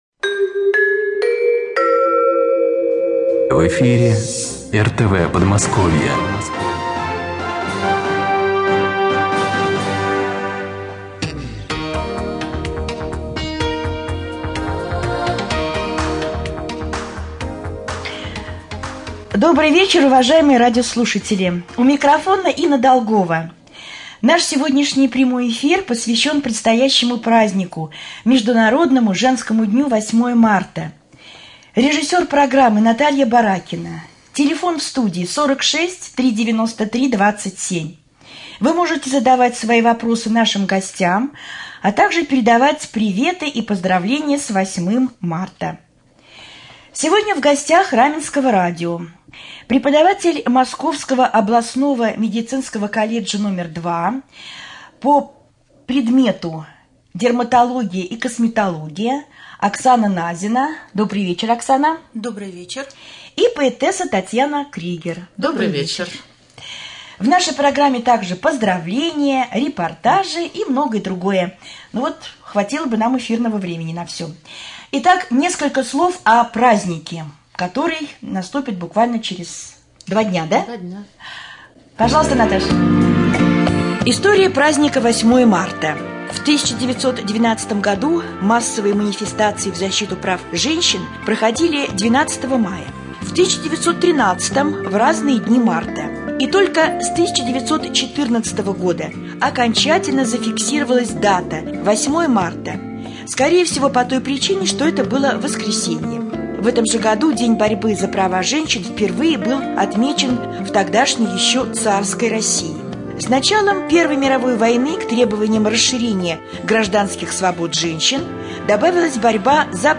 Прямой эфир посвященный 8 Марта